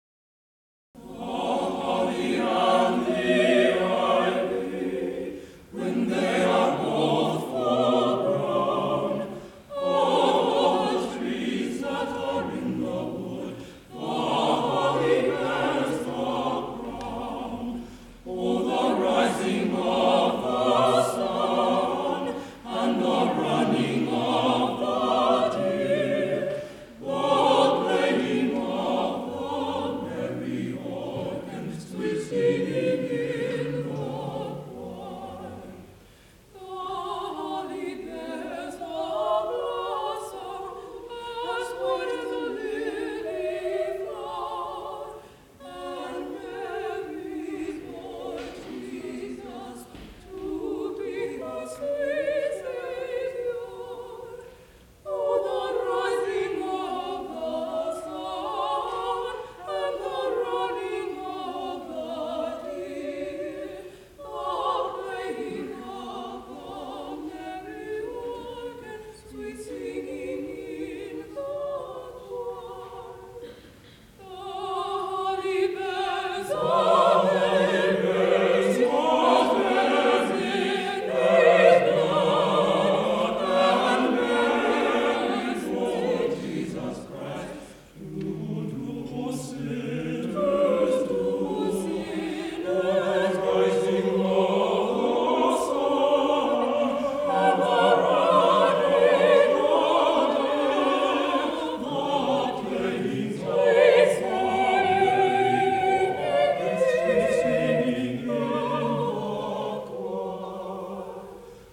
Highland Park, MI, High School Concert Choirs, 1954-1969